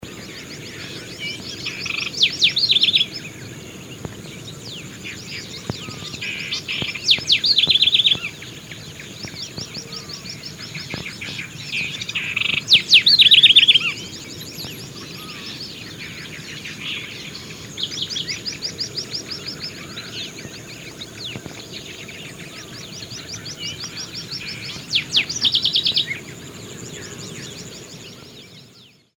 Chercán – Universidad Católica de Temuco
Chercan-Troglodytes-aedon.mp3